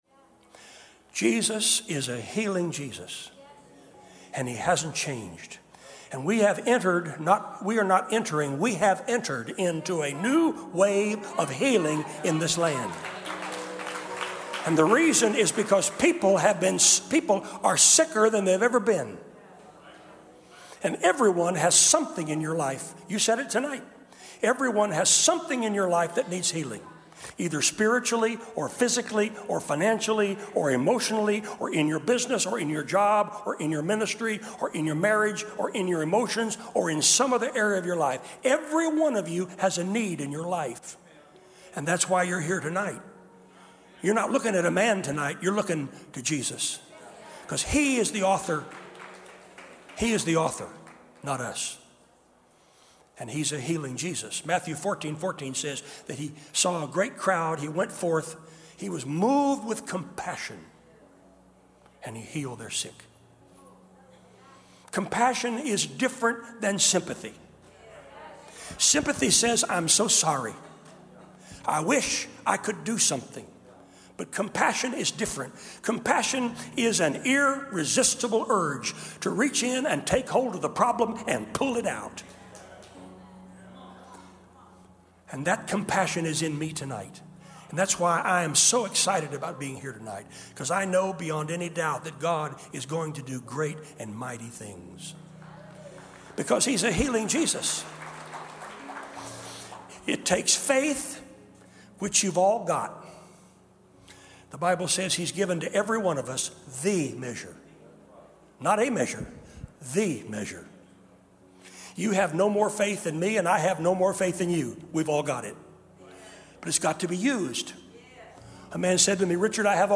1 What the Bible says About Healing 38:04 Play Pause 23h ago 38:04 Play Pause Play later Play later Lists Like Liked 38:04 In this episode you will step into a recent service at Regeneration Church in Nashville, TN to hear a faith-filled message on methods of healing found in the Bible, followed by healing prayer.